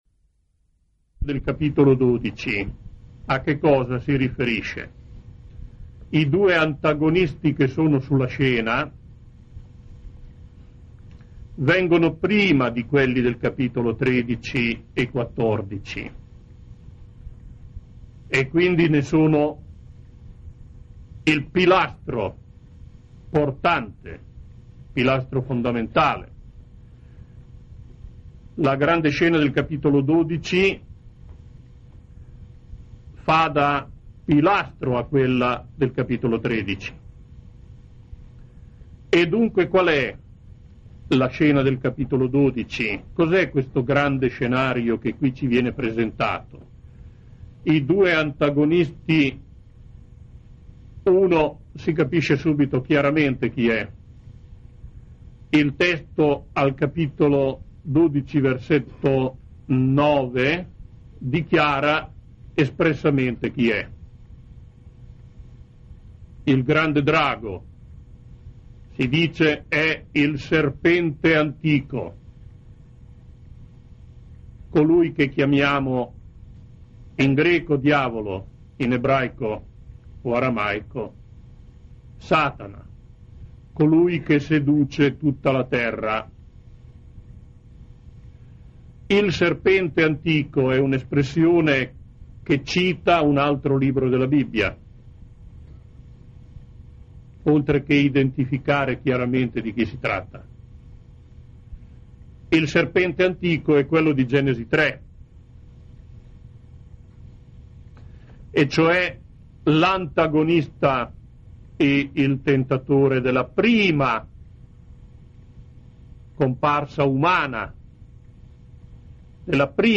Corso Teologico: Apocalisse